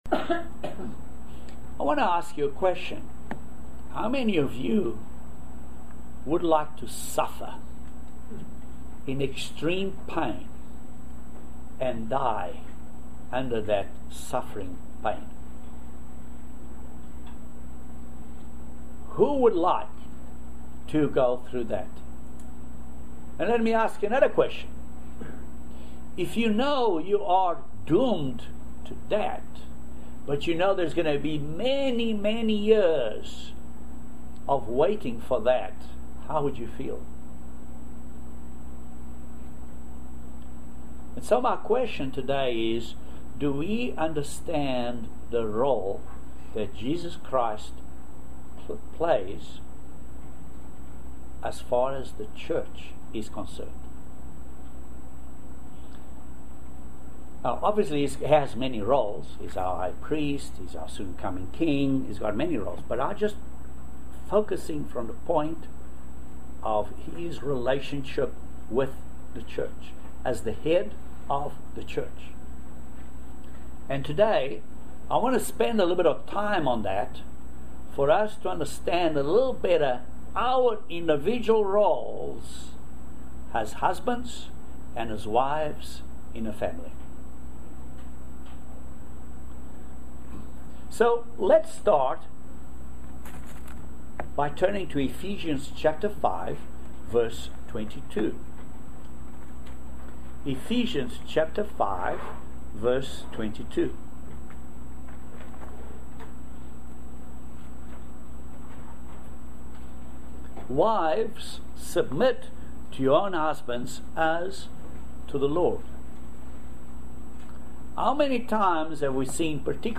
The answer to this question and more in this video sermon.